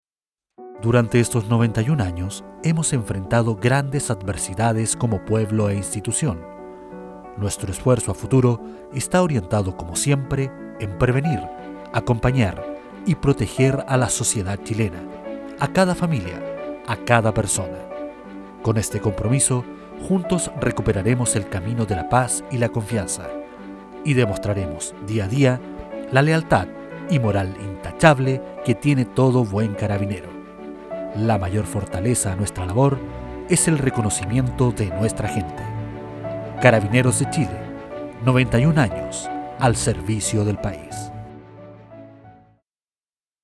chilenisch
Sprechprobe: Industrie (Muttersprache):
Spot Carabineros de Chile.mp3